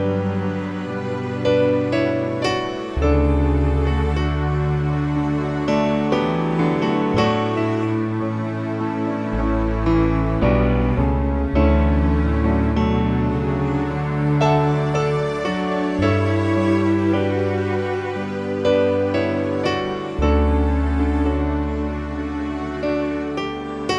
No Acoustic Guitars Christmas 4:02 Buy £1.50